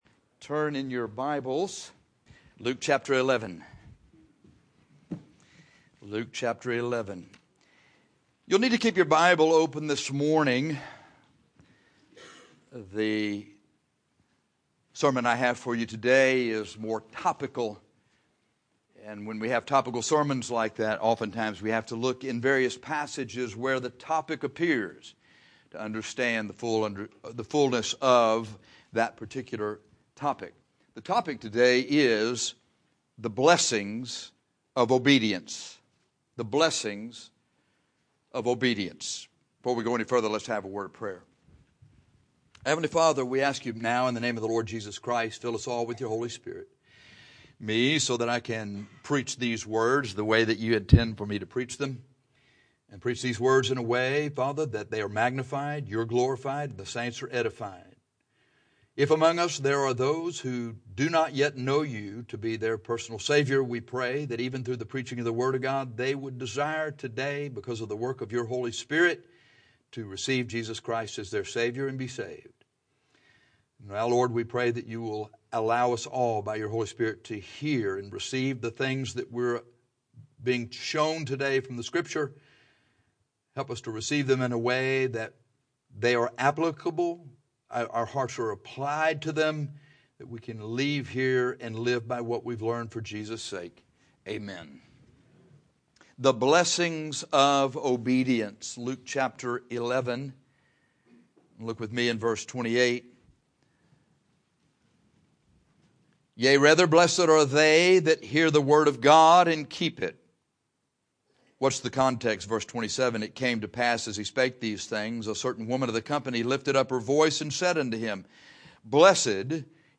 This sermon explores the blessings of obedience.